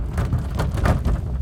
Wheel_loop.ogg